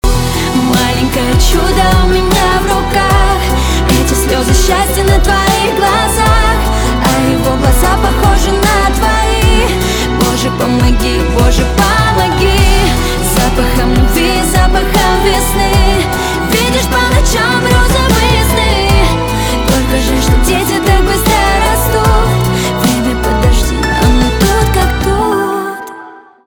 поп
чувственные
пианино , скрипка